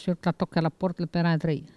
Maraîchin
Locution